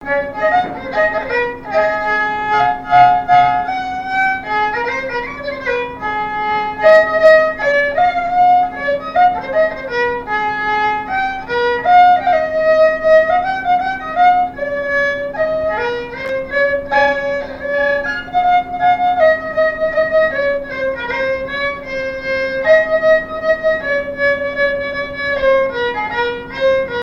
danse : java
Genre strophique
répertoire au violon et à la mandoline
Pièce musicale inédite